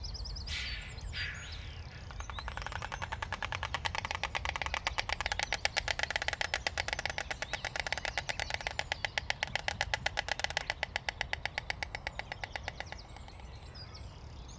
Cegoña branca
Canto
Ciguena-blanca.wav